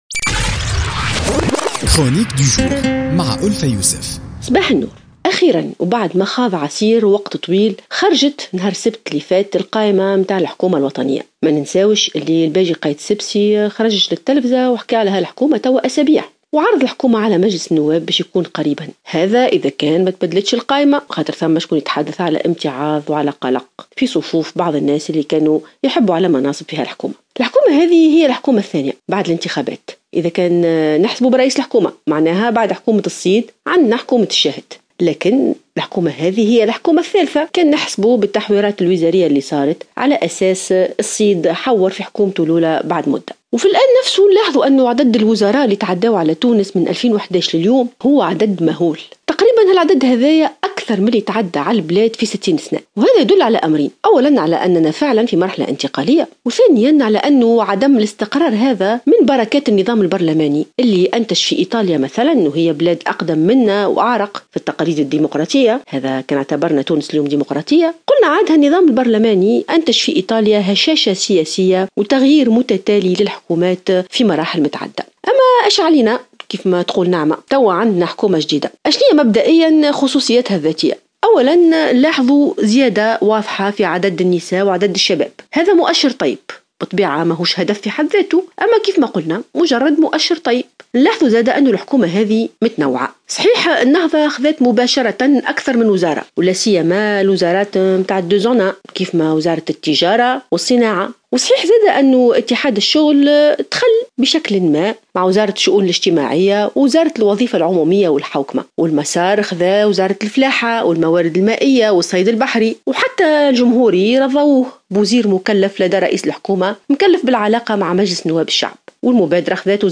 قالت الجامعية ألفة يوسف في افتتاحية اليوم الأربعاء إن حضور العنصر النسائي والشبابي في تشكيلة حكومة يوسف الشاهد يعتبر مؤشرا إيجابيا، لكنها ستجد نفسها أمام مشكل أساسي.